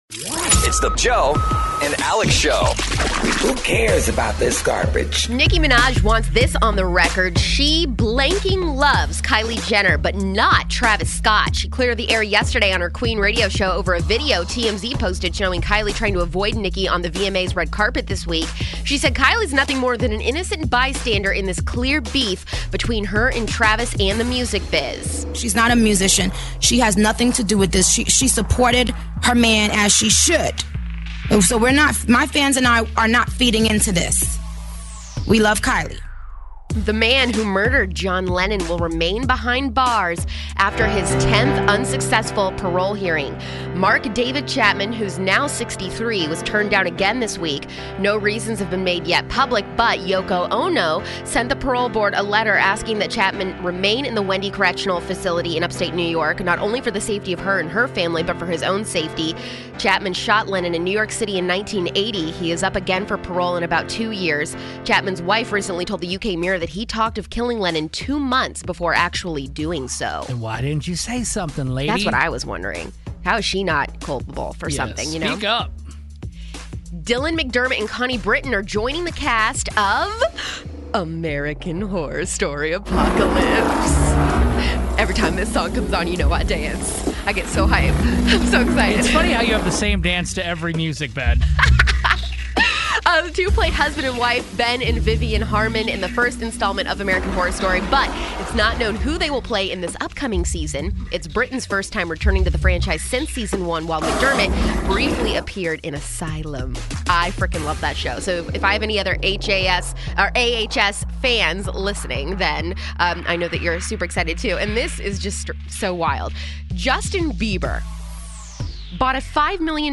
Audio of Nicki Minaj making it clear it's Travis Scott she has a problem with, not Kylie Jenner